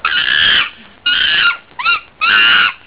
Monkey 1
MONKEY_1.wav